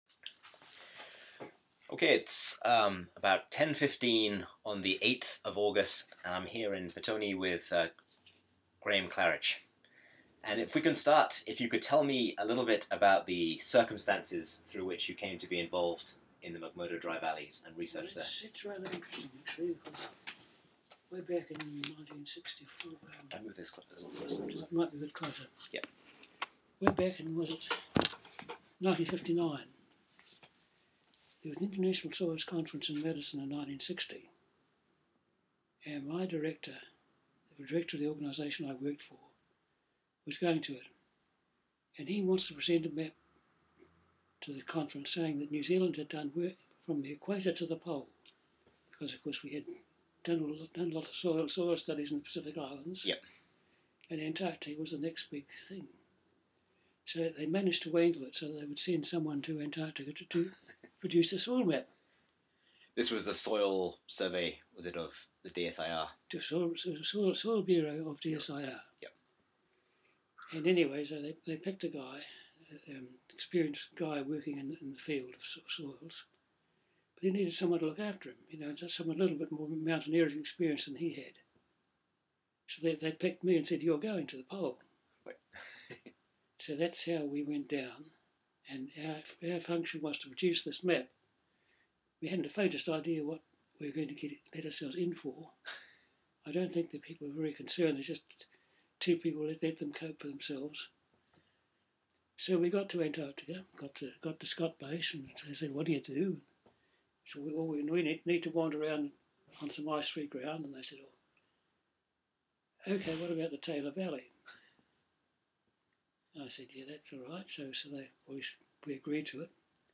Type: Interview